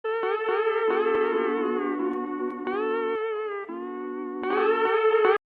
Spongebob Fail Sound Meme Sound Effect Download: Instant Soundboard Button